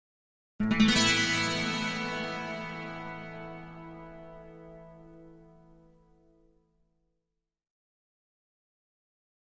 Acoustic Guitar - Minor Chord 2 - Thin And Echo